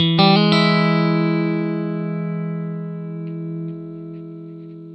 RIFF1-120E.A.wav